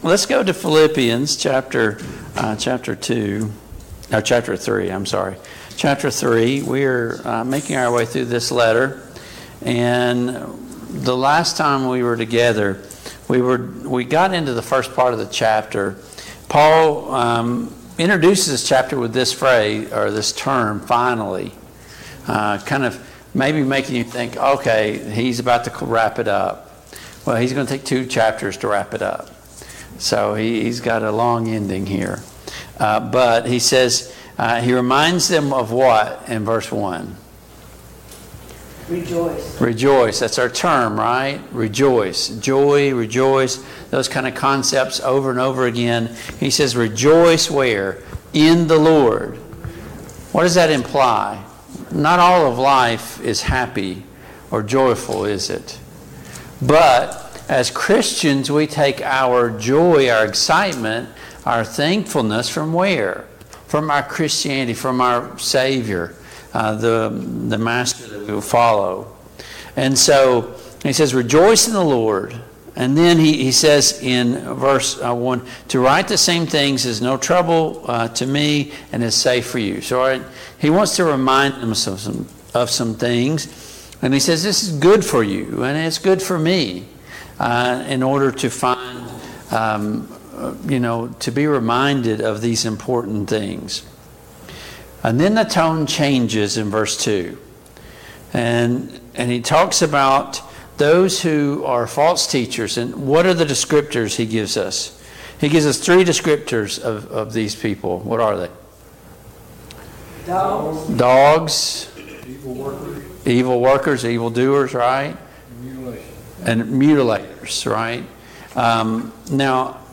Passage: Philippians 3:4-16 Service Type: Mid-Week Bible Study